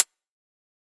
Index of /musicradar/ultimate-hihat-samples/Hits/ElectroHat C
UHH_ElectroHatC_Hit-03.wav